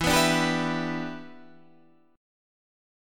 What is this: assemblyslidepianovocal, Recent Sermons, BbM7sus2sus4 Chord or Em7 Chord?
Em7 Chord